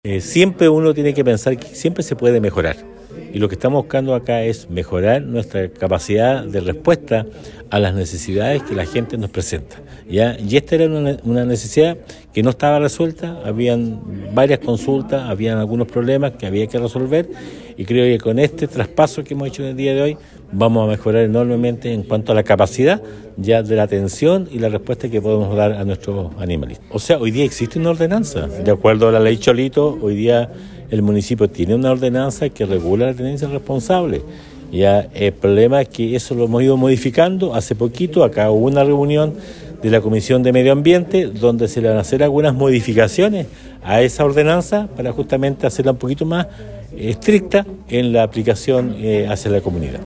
En cuanto a la ordenanza municipal que regula la materia, el jefe comunal indicó que esta se creó en base a la “Ley Cholito”, pero que se estudiarán hacerle modificaciones acordes a la época.